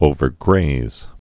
(ōvər-grāz)